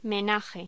Locución: Menaje
voz